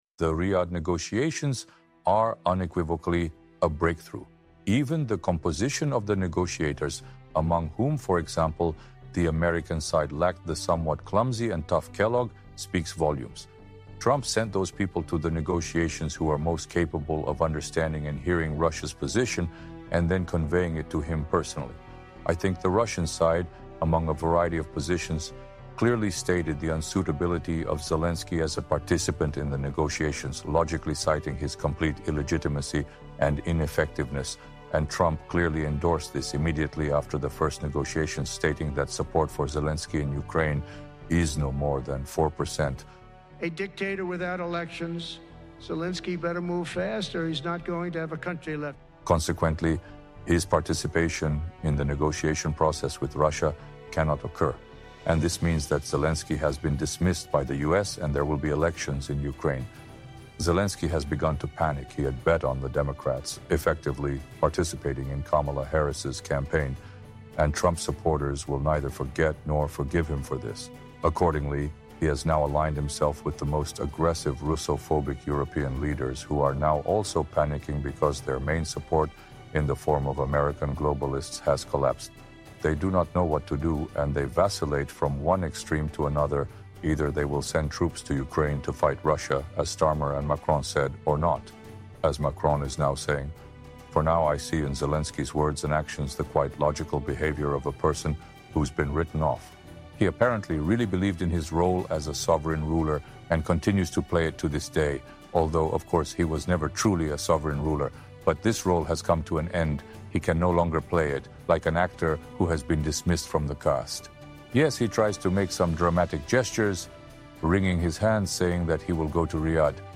Alexander Dugin's speech series on the Sptunik